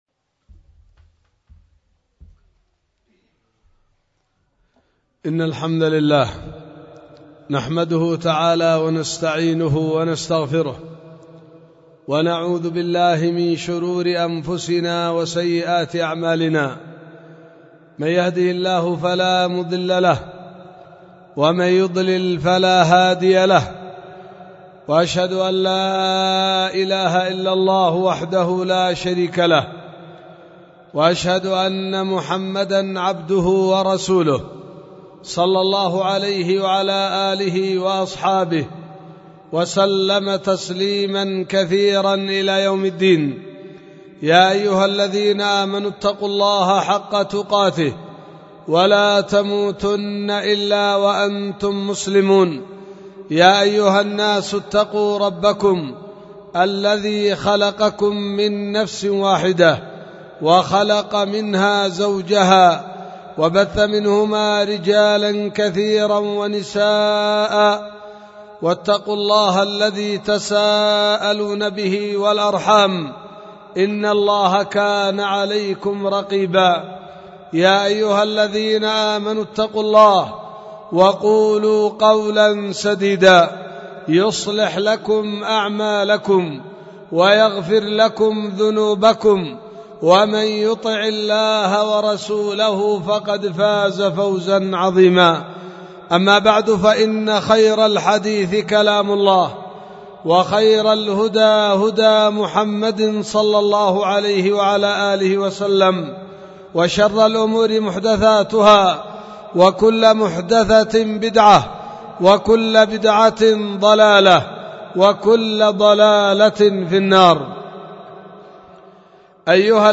خطبة ألقيت في 4 شعبان 1444 هجرية في دار الحديث بوادي بنا – السدة – إب – اليمن